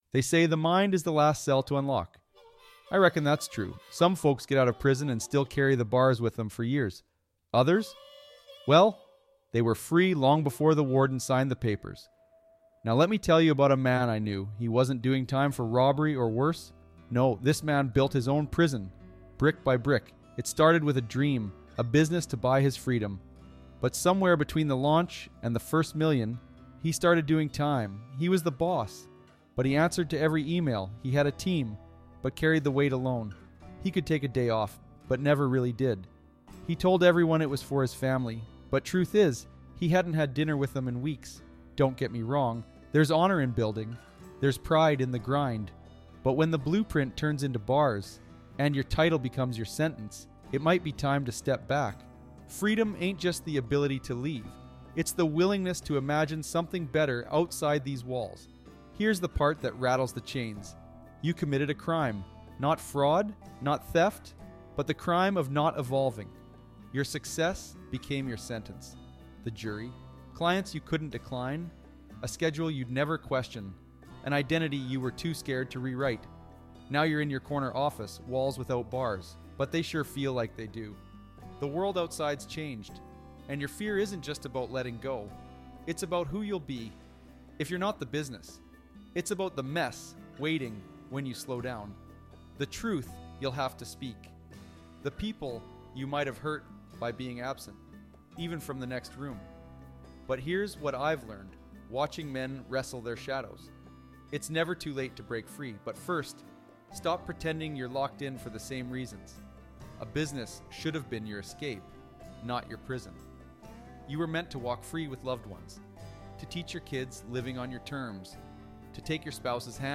channels the tone of Morgan Freeman to speak directly to the entrepreneur who's stuck—physically free, but mentally confined. This is a call to those who've forgotten why they started.